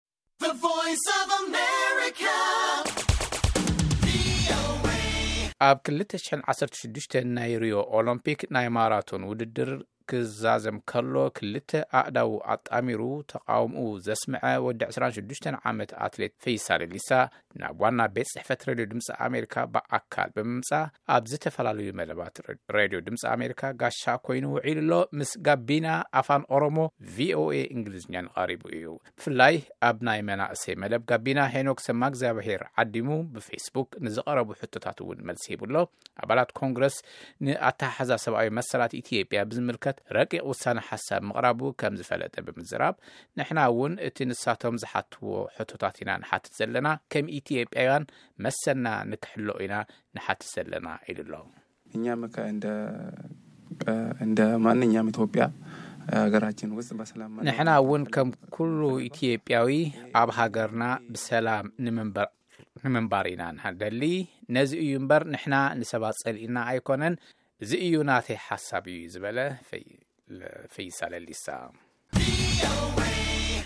ቃለ-መጠይቕ አትሌት ፈይሳ ሌሊሳ